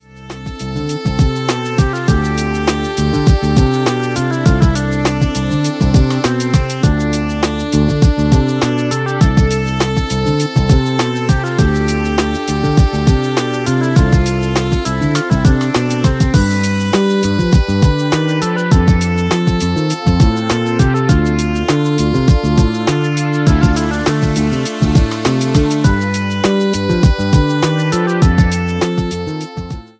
R&B SOUL  (03.54)